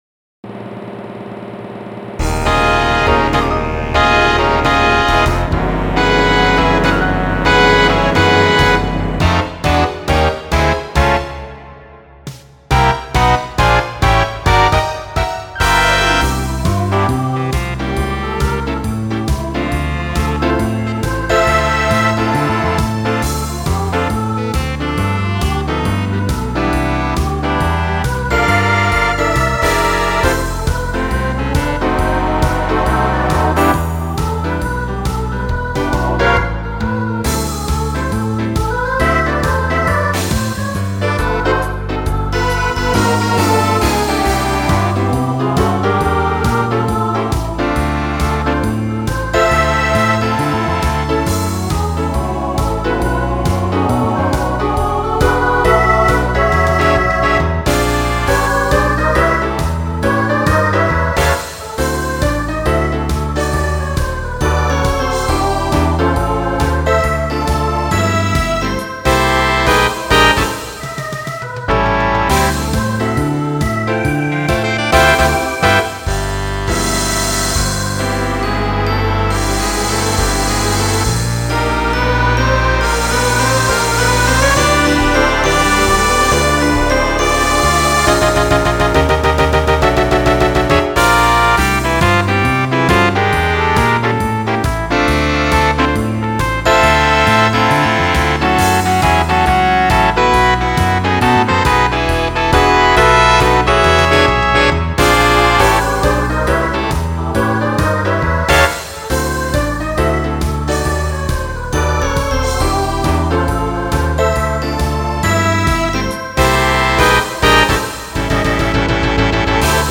Voicing SSA Instrumental combo Genre Broadway/Film
Mid-tempo